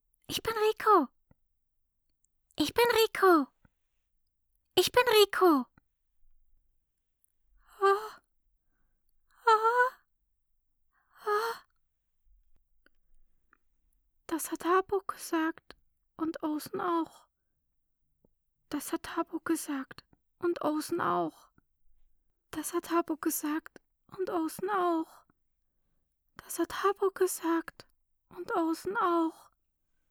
Made in Abyss Seelen der Finsternis Fandub